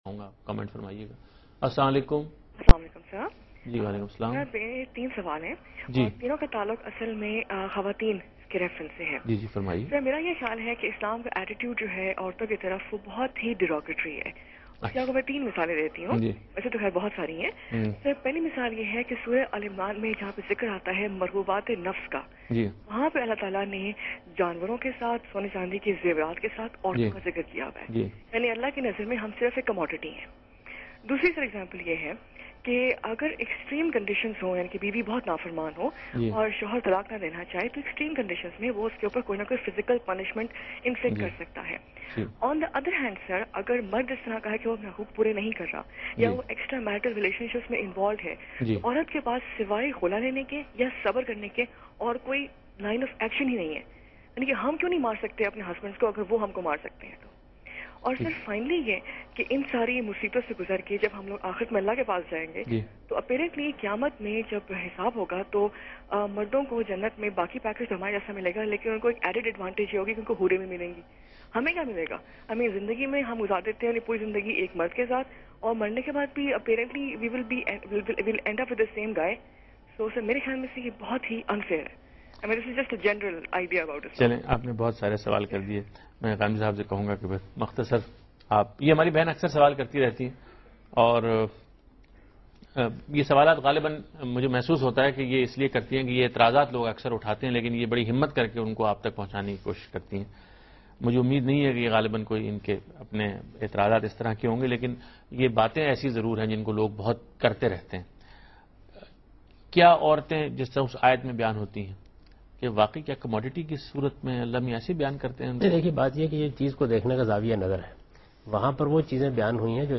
Category: TV Programs / Dunya News / Deen-o-Daanish /
Why God gave this authority to Husband?. Are Men and Women equal according to Islam. Javed Ahmad Ghamidi asnwers a question in TV show Deen o Danish aired on Dunya News.